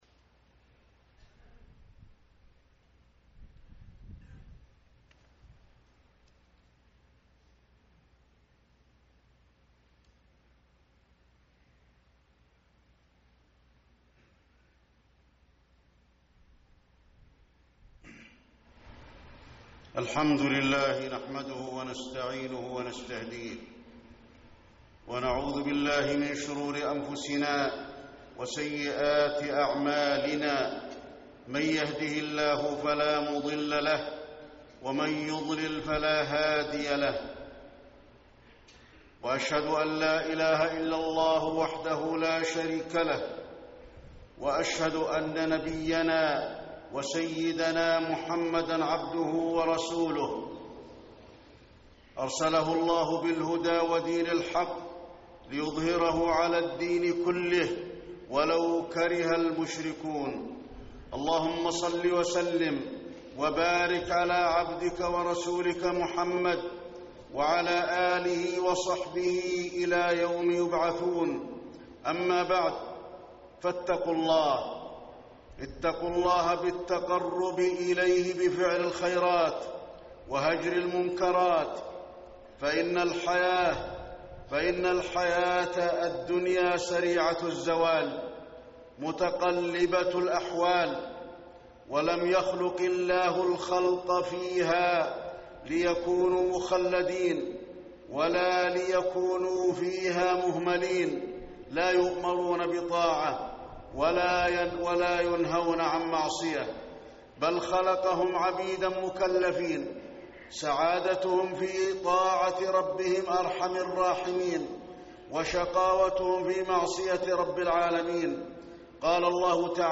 تاريخ النشر ٣ رجب ١٤٣٠ هـ المكان: المسجد النبوي الشيخ: فضيلة الشيخ د. علي بن عبدالرحمن الحذيفي فضيلة الشيخ د. علي بن عبدالرحمن الحذيفي غفلة القلب